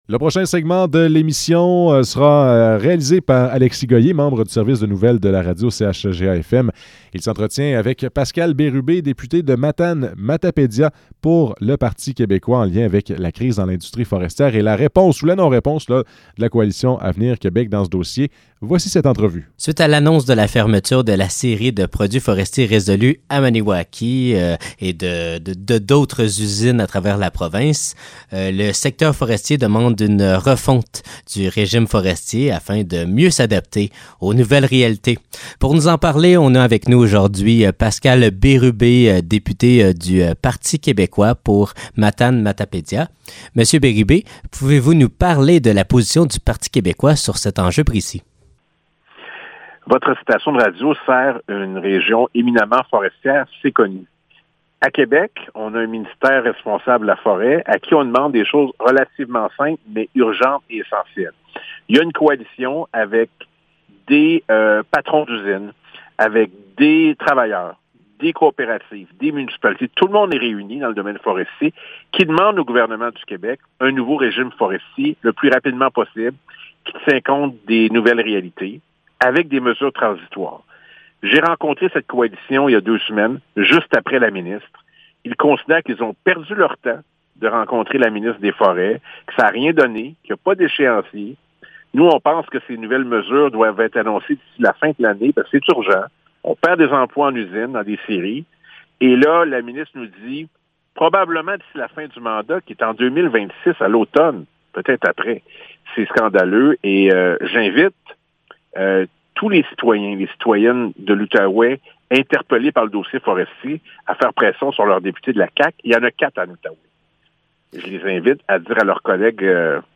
Entrevue avec Pascal Bérubé